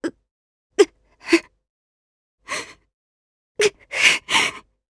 Glenwys-Vox_Sad_jp.wav